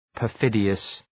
Προφορά
{pər’fıdıəs}